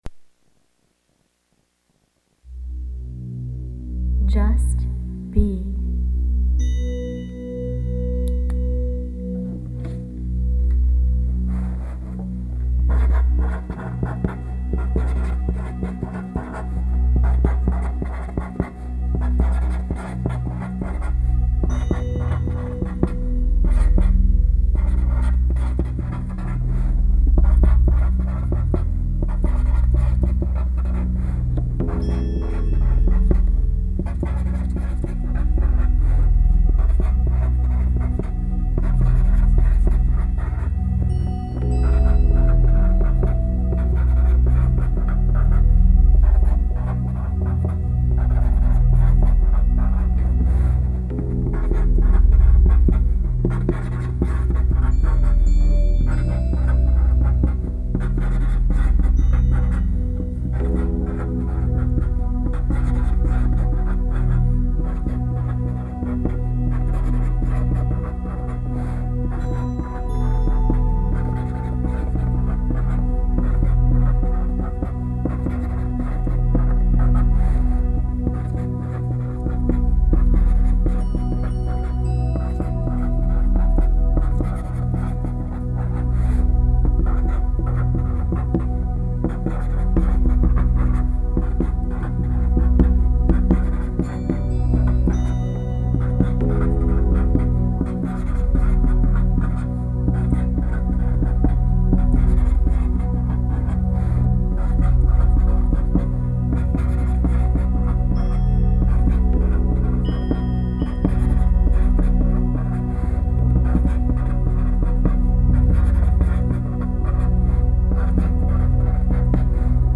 Meditative Music for You!
MP3 audio file of original meditative music heard in video above.
Can you hear the change in rhythm near the beginning of the recording when I momentarily lost concentration and penned an E instead of a B, then wrote over it before continuing?